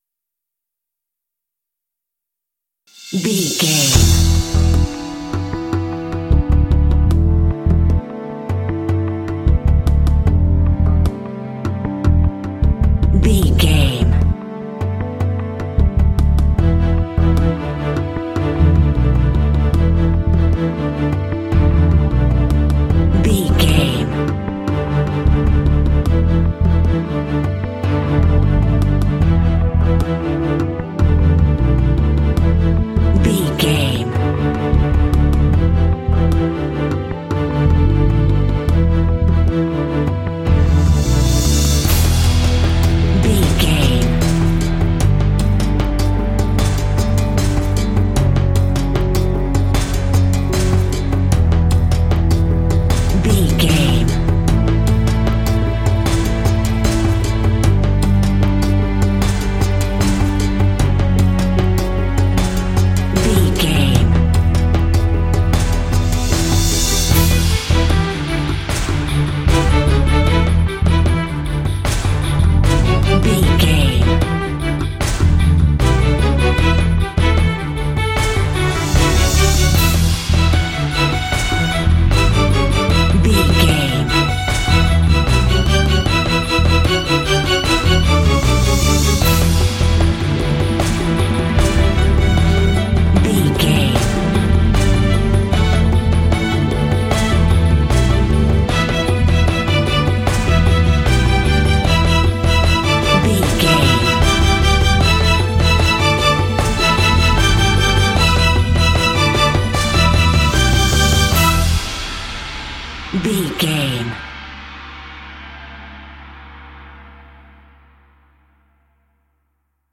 Fast paced
Aeolian/Minor
dramatic
foreboding
suspense
strings
bass guitar
orchestral
symphonic rock